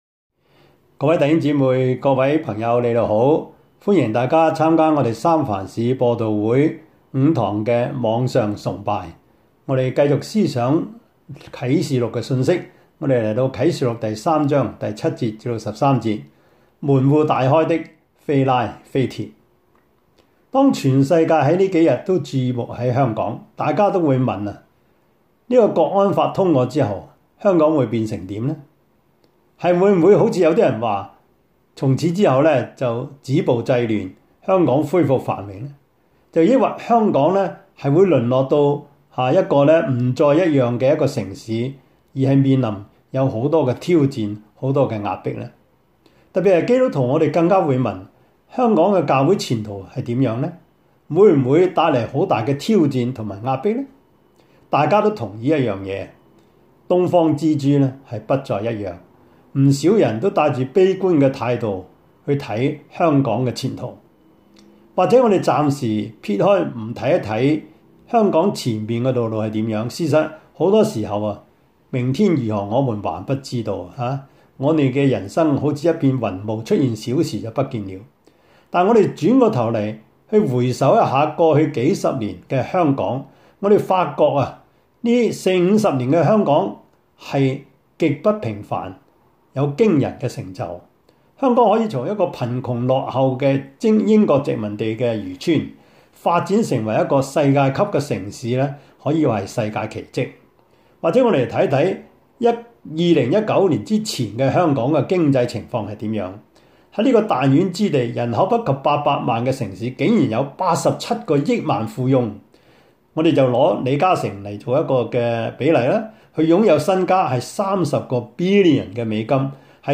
Service Type: 主日崇拜
Topics: 主日證道 « 神施恩的手 第六課：早期教會與猶太人的關係 »